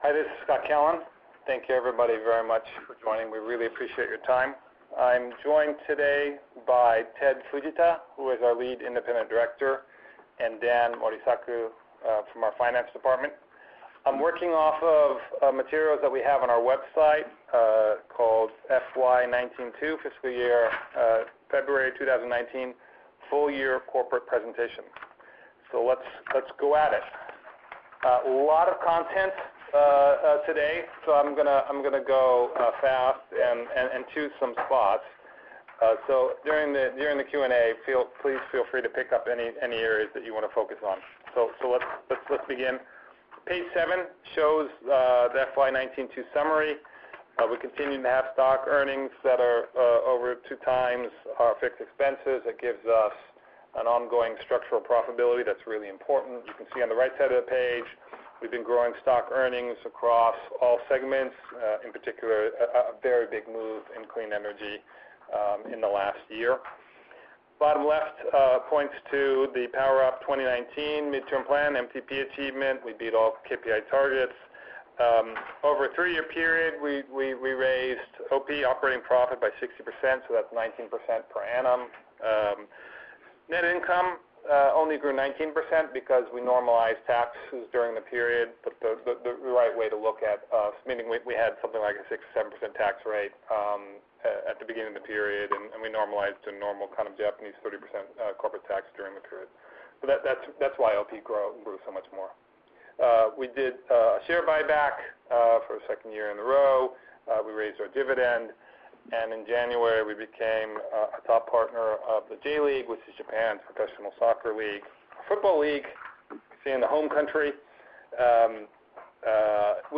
FY20/2 Q1 Earnings Call